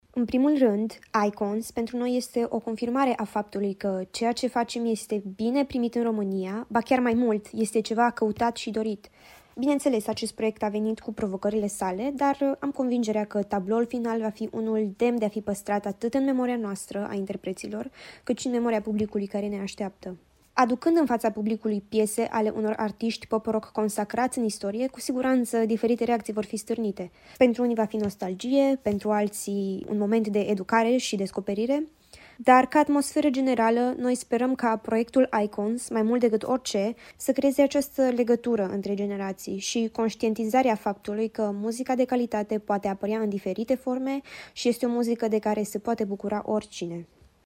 Interviuri cu protagoniștii proiectului - Radio România Timișoara